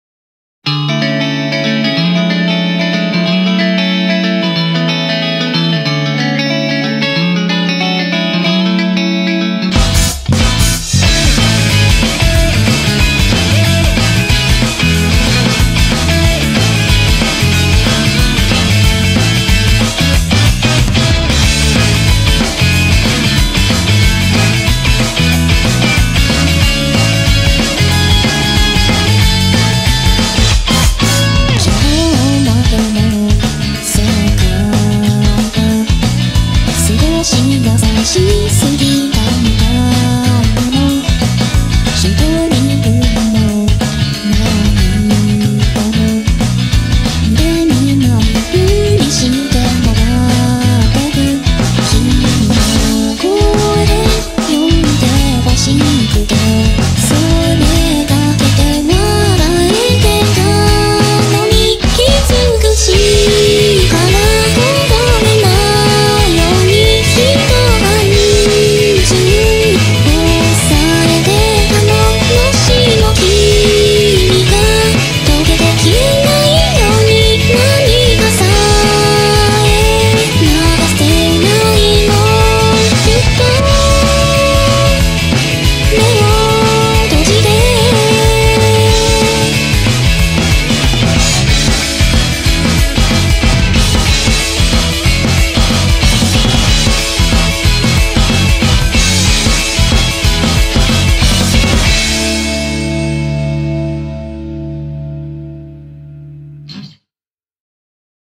BPM185
Audio QualityPerfect (Low Quality)